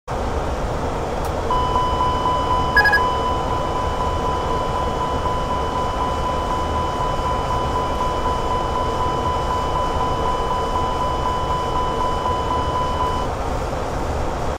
Play MAster Caution AIrbus - SoundBoardGuy
Play, download and share MAster caution AIrbus original sound button!!!!
master-caution-airbus.mp3